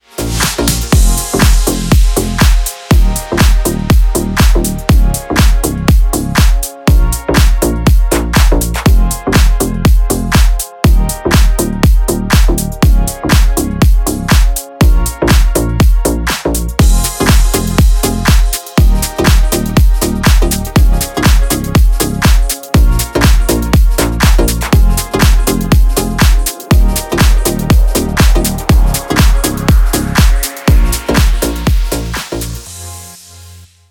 клубные # без слов